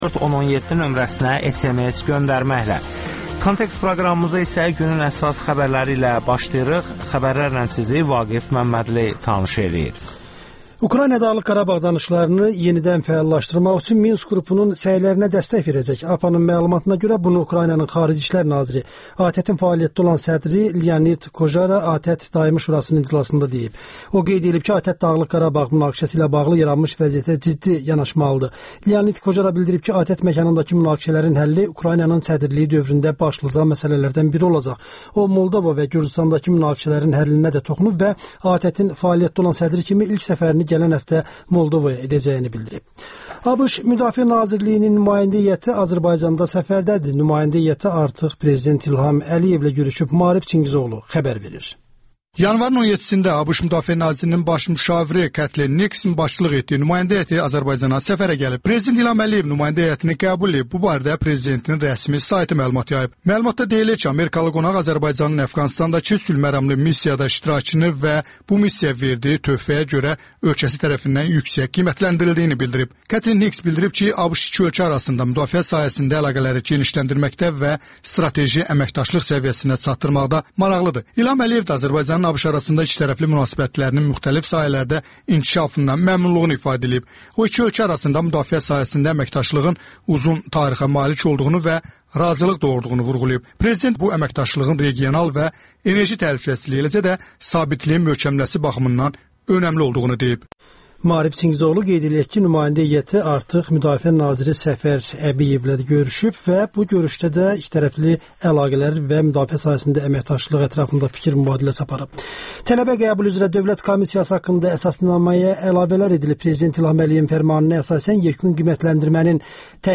Kontekst - Kinomatoqrafçılar İttifaqına cinayət işi nəyə görə açılıb? Rasim Balayevlə müsahibə